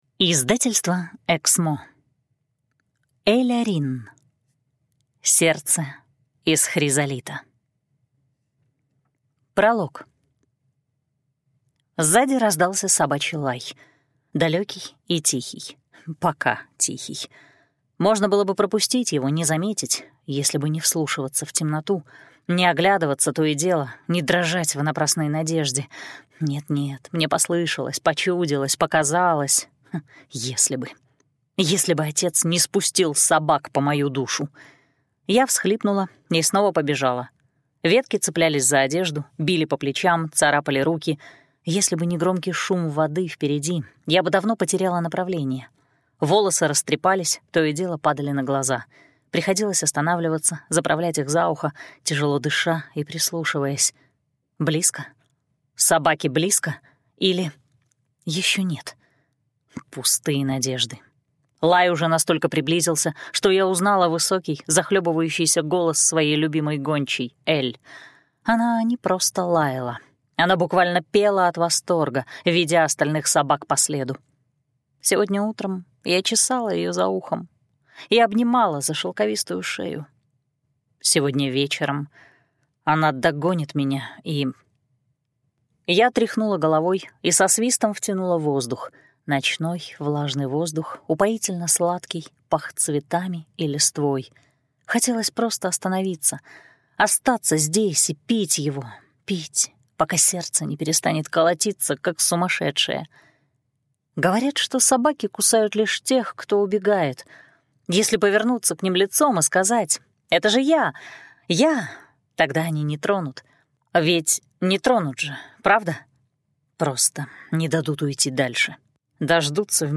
Аудиокнига Сердце из хризолита | Библиотека аудиокниг